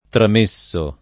[ tram %SS o ]